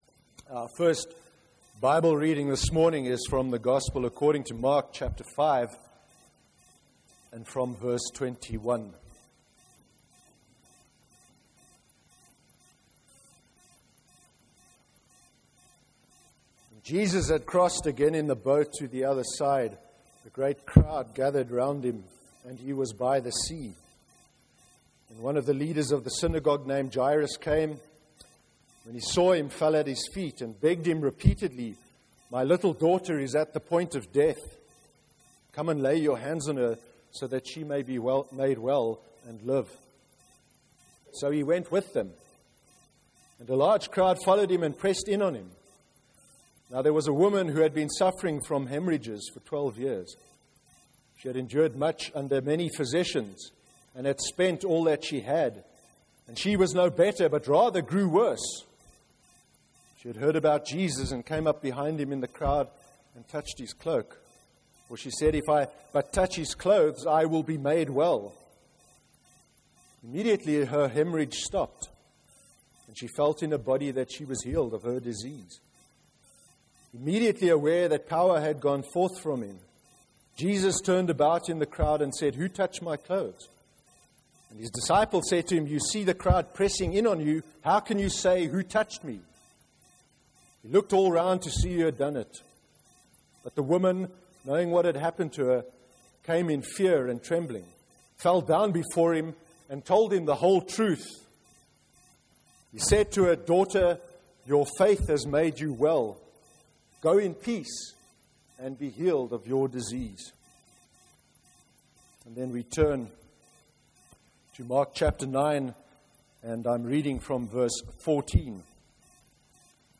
09/02/2014 sermon. What lies at the heart of Christianity.